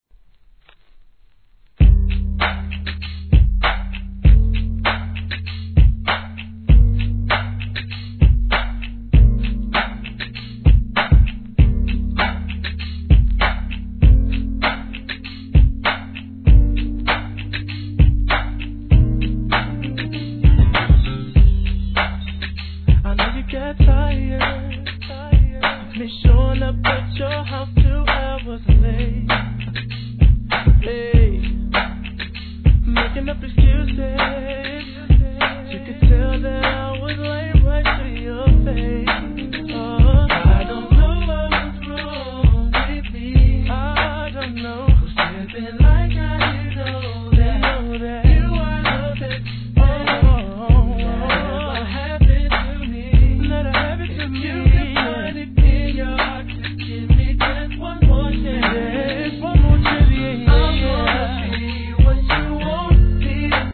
HIP HOP/REGGARE/SOUL/FUNK/HOUSE/